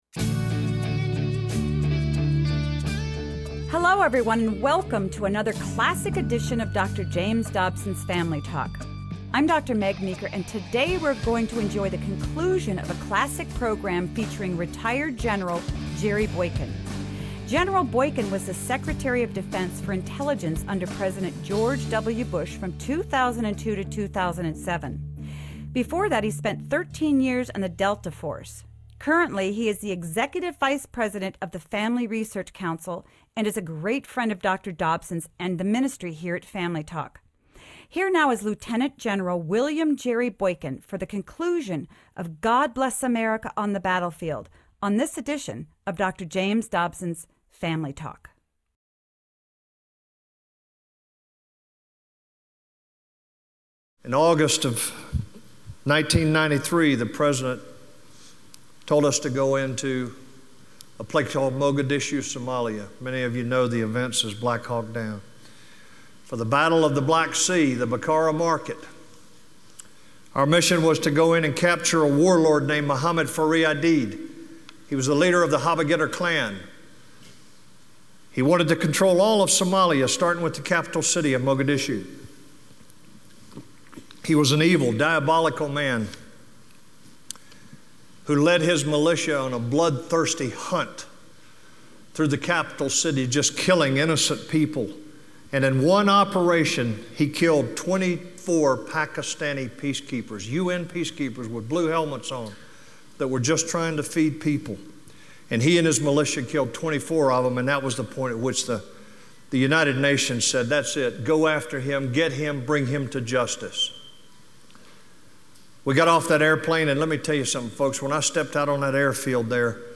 Now imagine that song sung by soldiers on the battlefield during war. General Jerry Boykin recalls this powerful story.